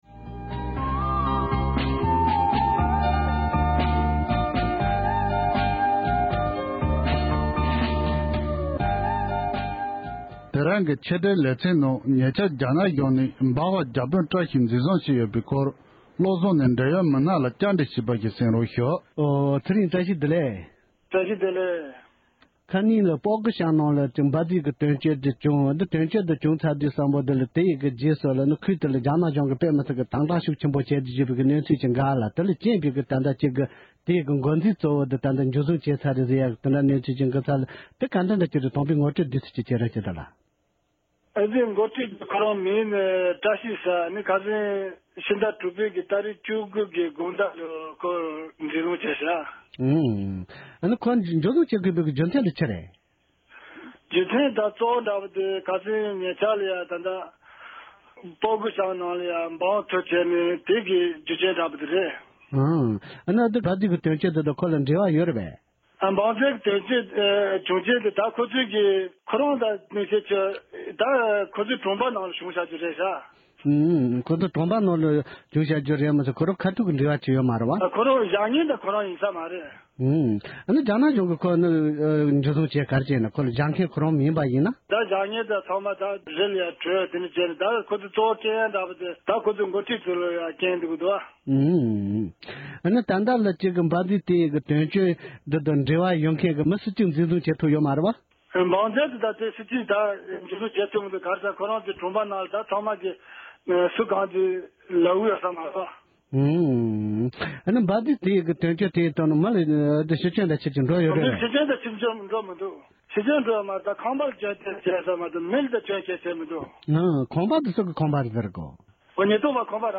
སྒྲ་ལྡན་གསར་འགྱུར།
འབྲེལ་ཡོད་མི་སྣ་ཞིག་ལ་བཀའ་འདྲི་ཞུས་པ་ཞིག་ལ་གསན་རོགས་ཞུ༎